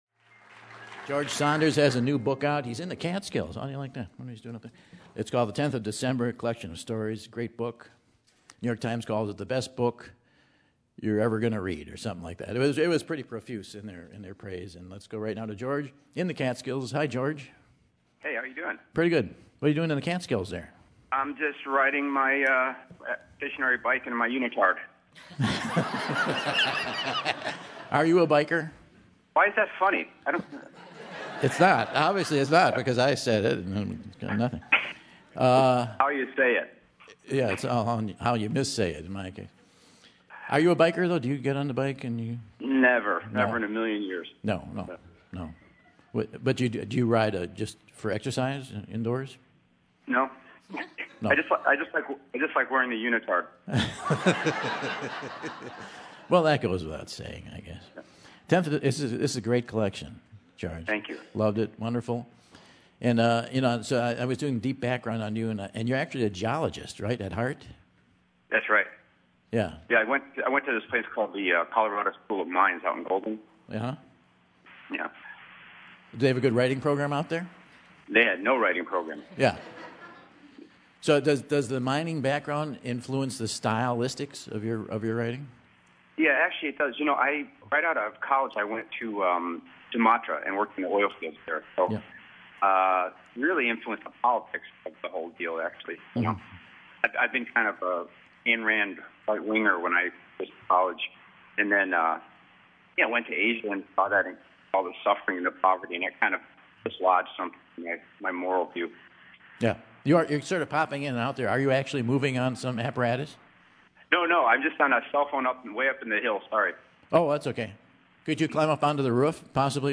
Author George Saunders is considered by some to be the best short-story writer alive. He's on the show to chat about his new book "Tenth of December"!